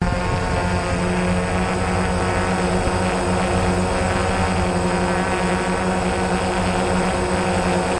Phantom Quadcopter sounds " Phantom Quadcopter Hovers
描述：幻影在车站上盘旋，车辆噪音相当稳定。
Tag: 电机 飞行 悬停 四轴 幻影